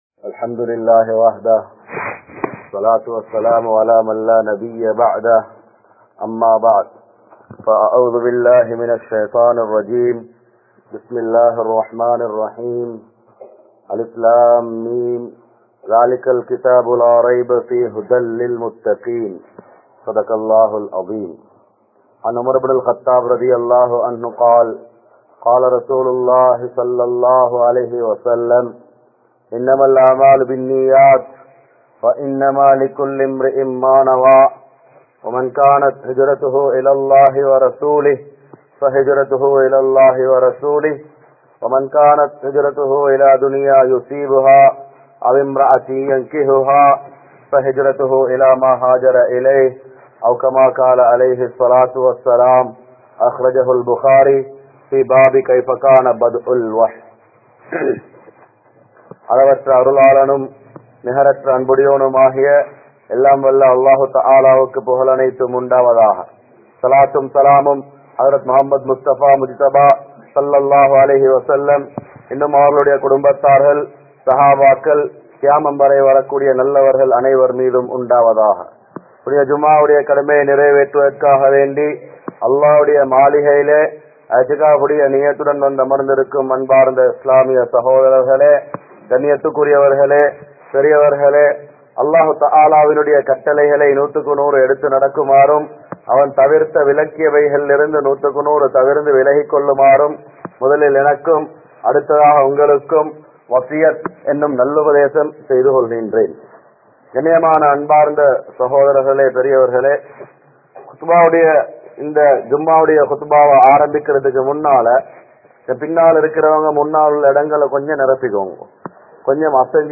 Nirantharamillaatha Ulaha Vaalkai (நிரந்தரமில்லாத உலக வாழ்க்கை) | Audio Bayans | All Ceylon Muslim Youth Community | Addalaichenai
Majmaulkareeb Jumuah Masjith